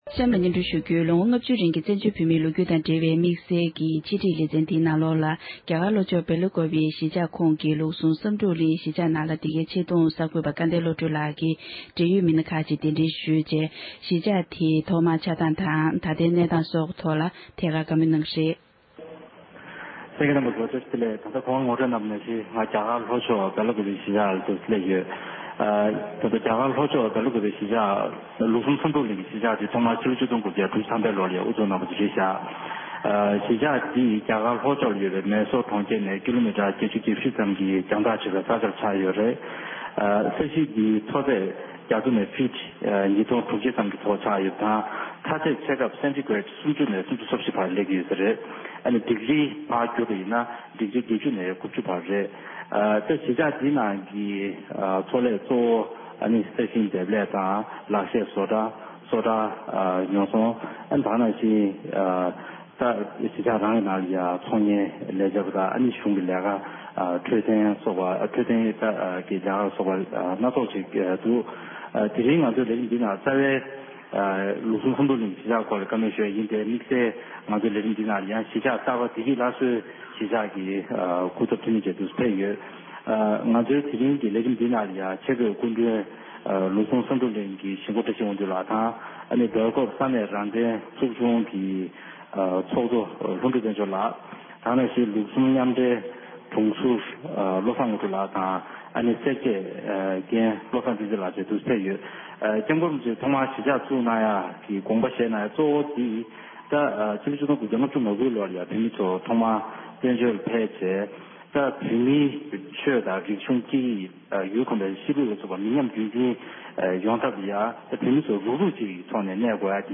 བཙན་བྱོལ་ནང་གི་བོད་མིའི་གཞིས་ཆགས་དང་པོ་ལུགས་བཟུང་བསམ་གྲུབ་གླིང་ཐོག་མར་ཆགས་ཚུལ་གྱི་སྐོར་གླེང་མོལ་ཞུས་པ།